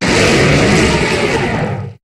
Cri d'Ékaïser dans Pokémon HOME.